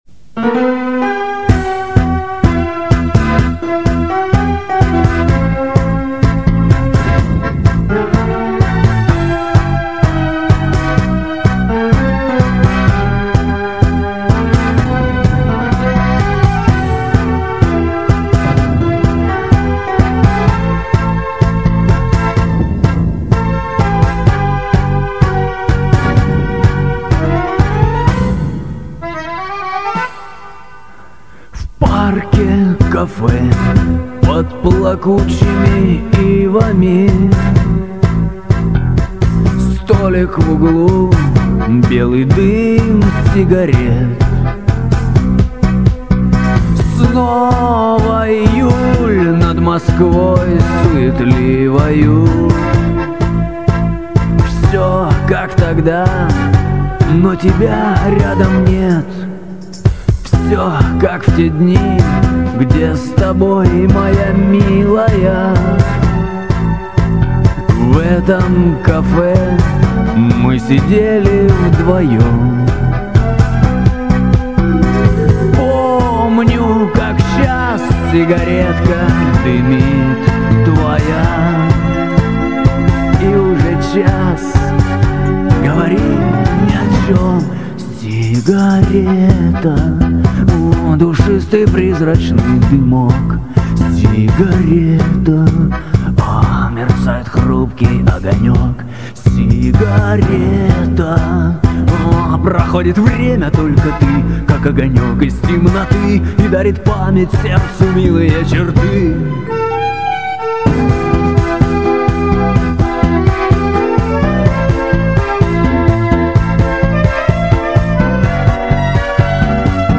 Шансон
Шикарное танго))